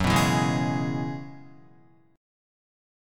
Gb9sus4 chord